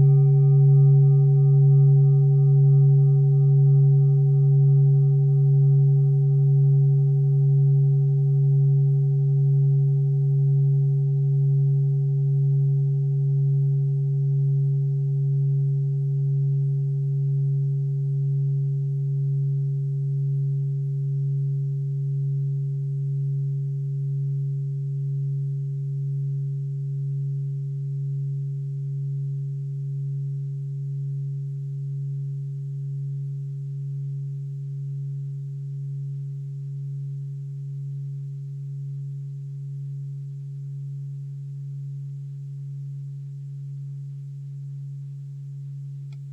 Klangschalen-Typ: Bengalen
Klangschale Nr.4
(Aufgenommen mit dem Filzklöppel/Gummischlegel)
klangschale-set-2-4.wav